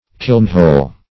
Kilnhole \Kiln"hole`\, n. The mouth or opening of an oven or kiln.